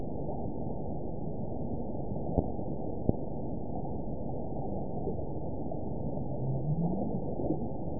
event 922876 date 04/30/25 time 16:33:21 GMT (7 months ago) score 7.40 location TSS-AB01 detected by nrw target species NRW annotations +NRW Spectrogram: Frequency (kHz) vs. Time (s) audio not available .wav